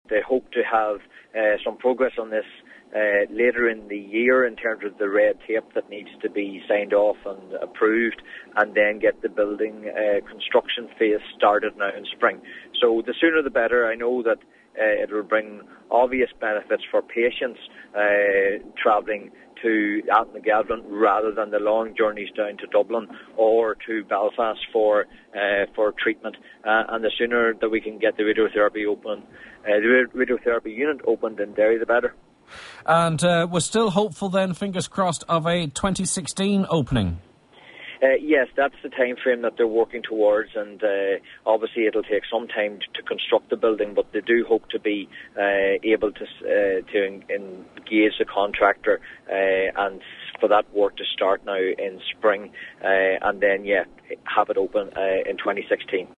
He says it’s hoped that construction can commence early next year……….